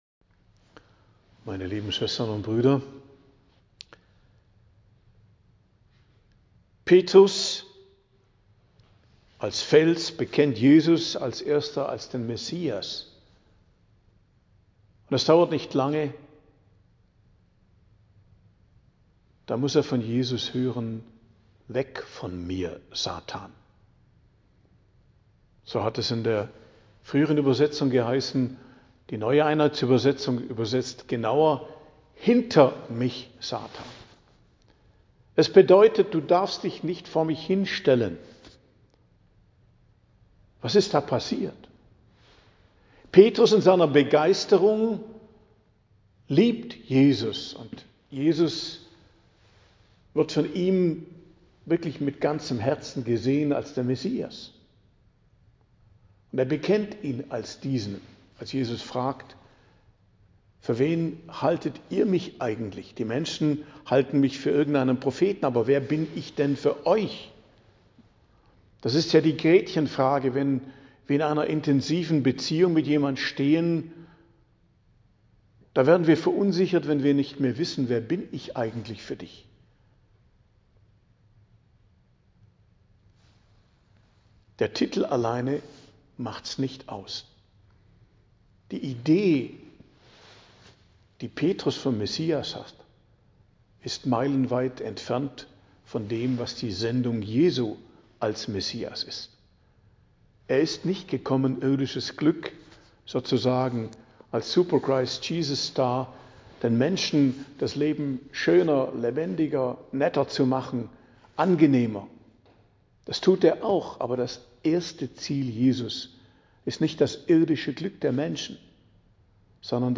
Predigt am Donnerstag der 6. Woche i.J. 20.02.2025